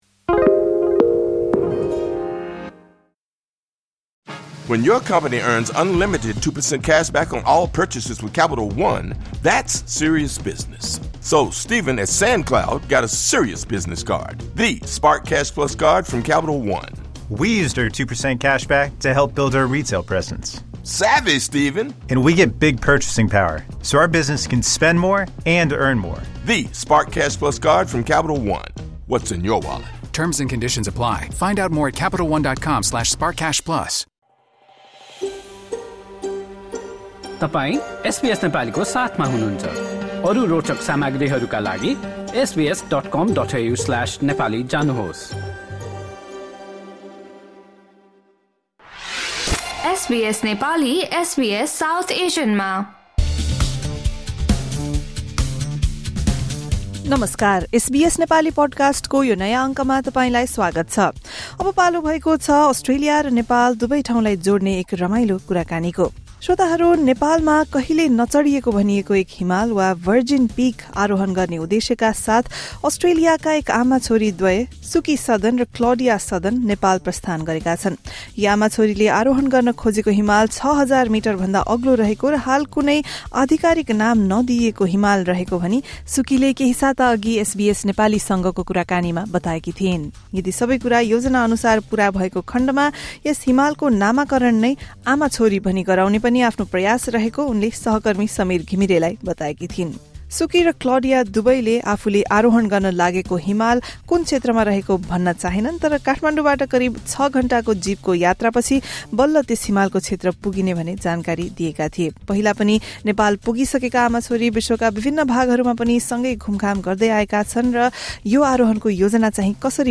उनीहरूसँग एसबीएस नेपालीले गरेको कुराकानी सुन्नुहोस्।